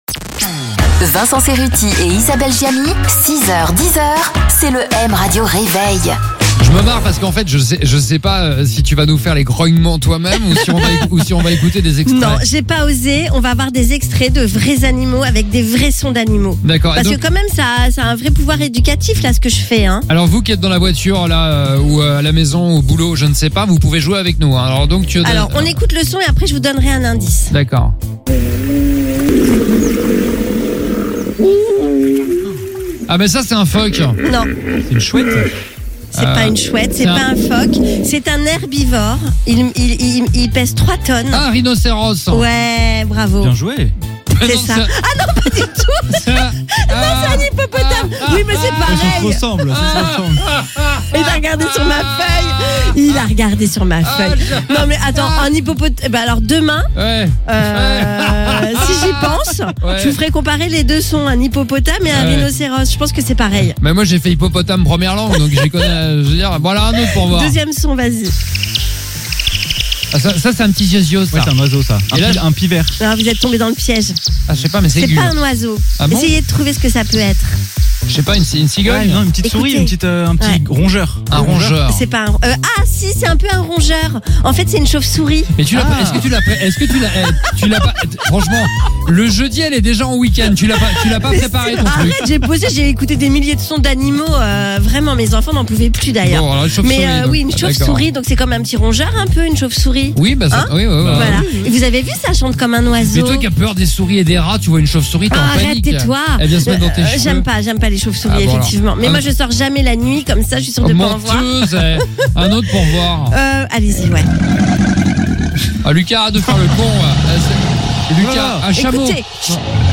On va faire un quizz sons d’animaux !